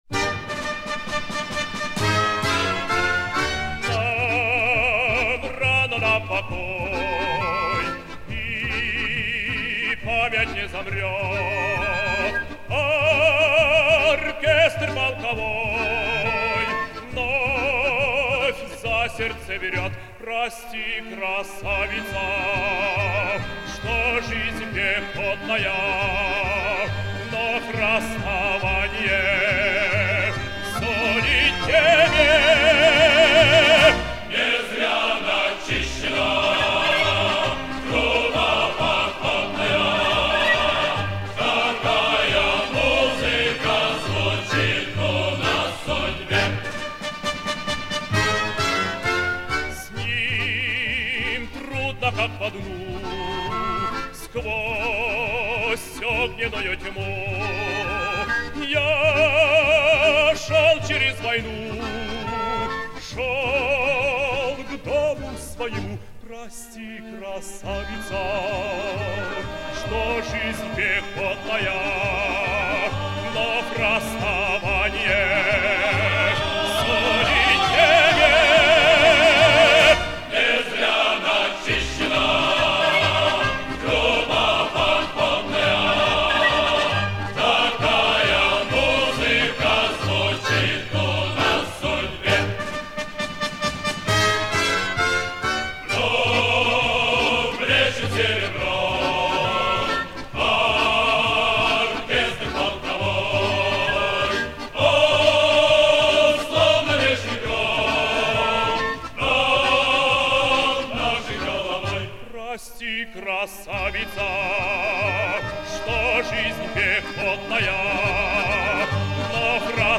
Песня в отличном качестве. С немецкой пластинки.